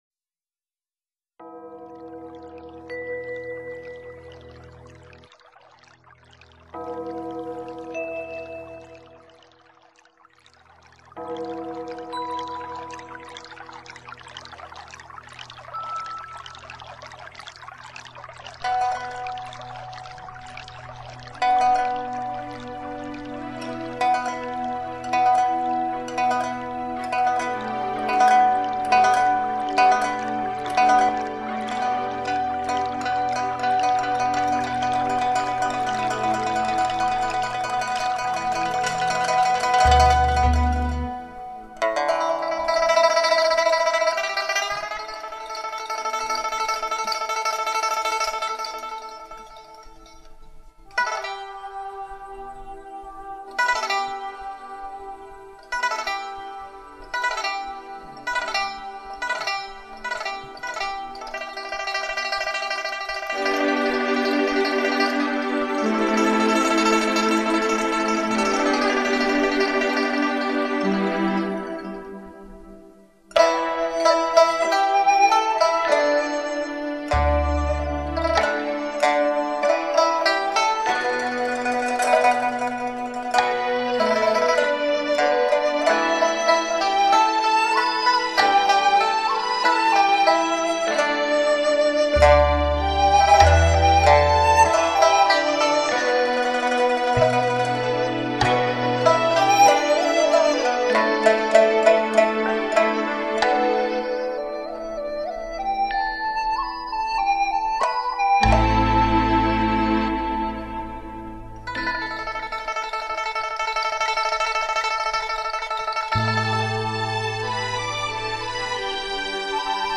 全面超越传统CD格式
营造360度音场效果